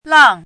làng
拼音： làng
注音： ㄌㄤˋ